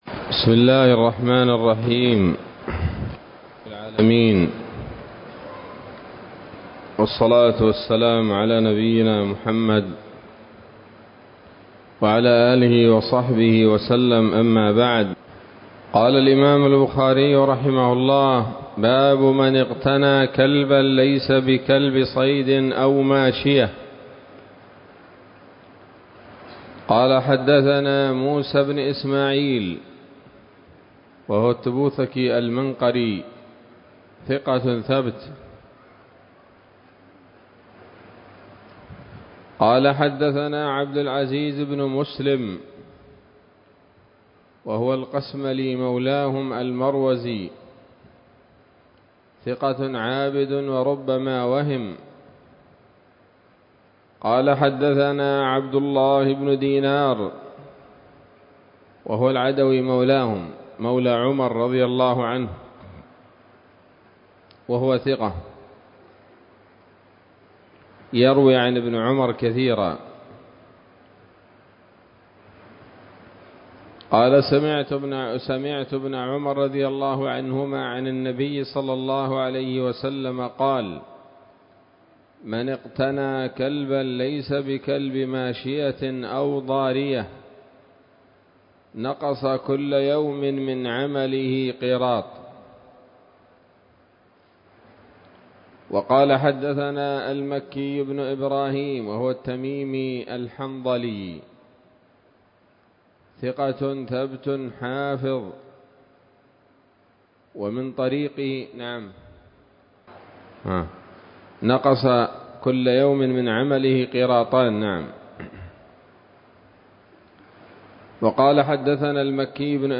الدرس السادس من كتاب الذبائح والصيد من صحيح الإمام البخاري